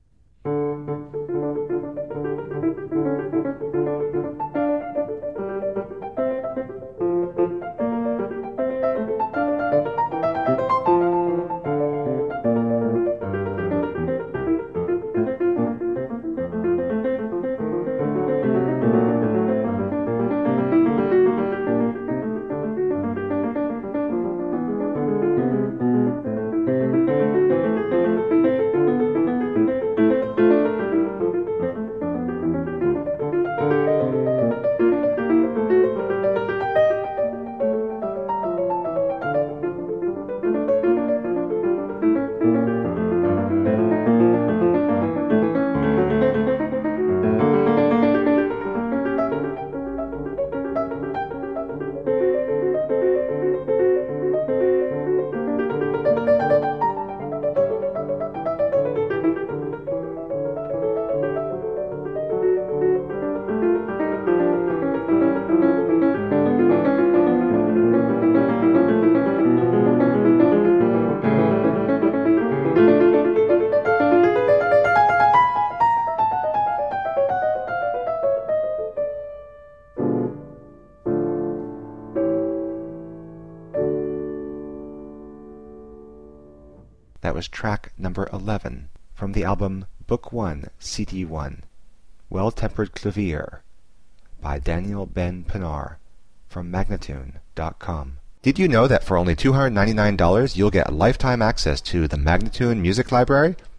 Virtuoso pianist
Classical, Baroque, Instrumental Classical, Classical Piano